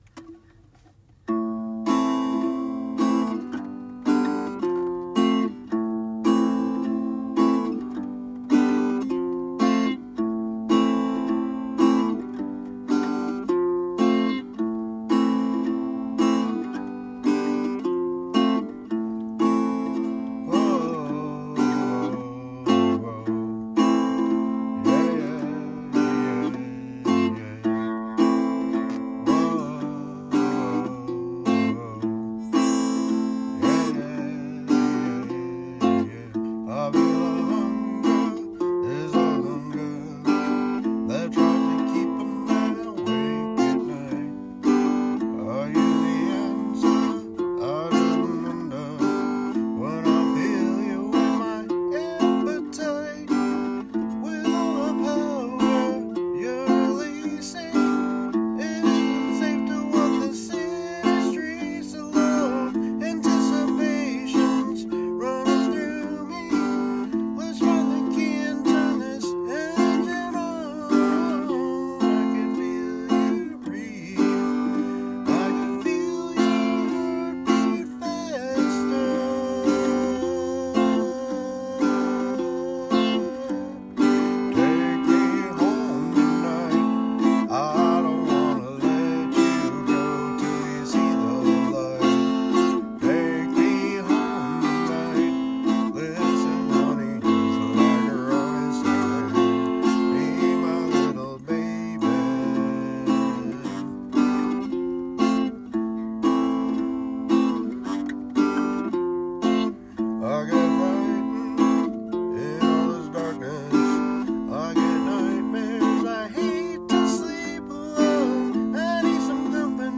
LOCATION: Back seat of my car @ local park
Guitar: Martin Backpacker Steel String
Recording: Work Phone
my work phone.  Very rough.